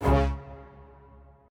strings4_8.ogg